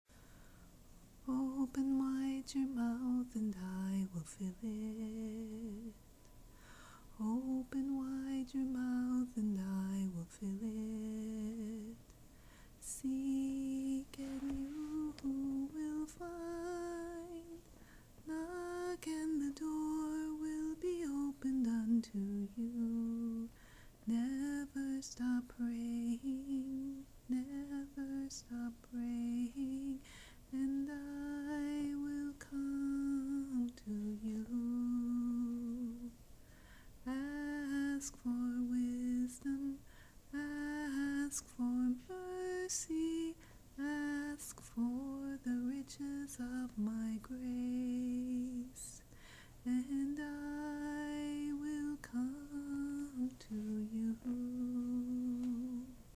The Lord asked me to take a brave step this week, and sing for you the song He gave me many years ago.
This Spirit Song was given to me during a time of prayer and Scripture reading in the spring of 2000.